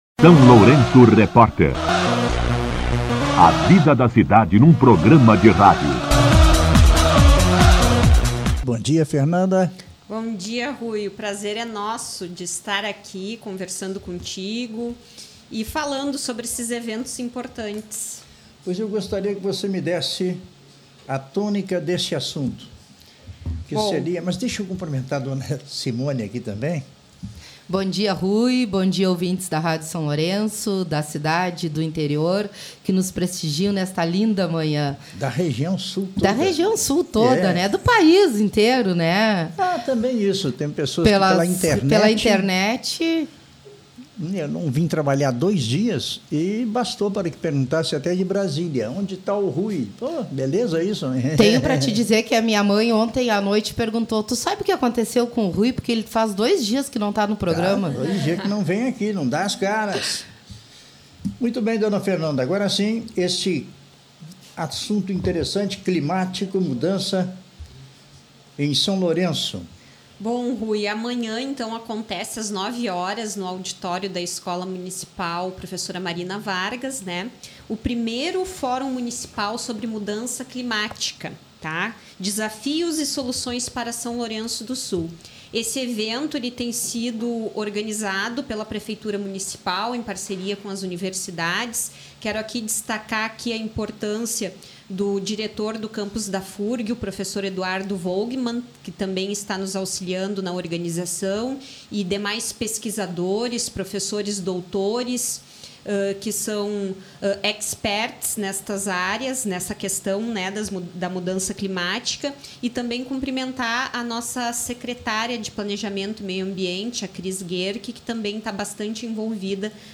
Entrevista com a vice-prefeita Fernanda Bork
A vice-prefeita Fernanda Bork esteve no SLR RÁDIO nesta quinta-feira (16) para comentar sobre o 1º Fórum Municipal sobre Mudança Climática, que acontece nesta sexta-feira, 17 de outubro, às 9h, no Auditório da Escola Marina Vargas.